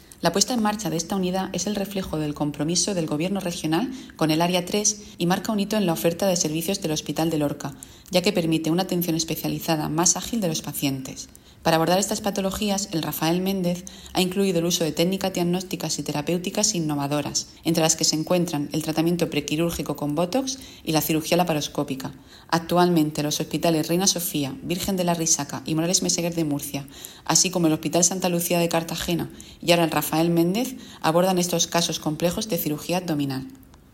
Declaraciones de la gerente del SMS, Isabel Ayala, sobre la nueva unidad de cirugía de pared abdominal del hospital de Lorca.